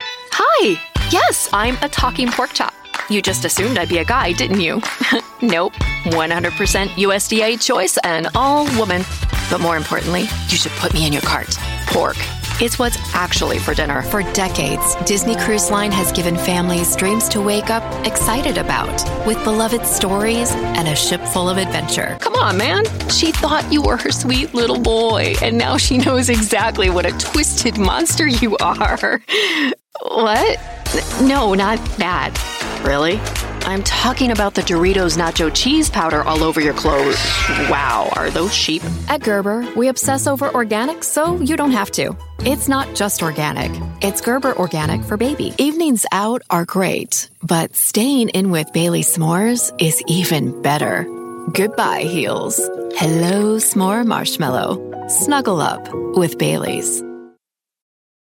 Commercial Voiceover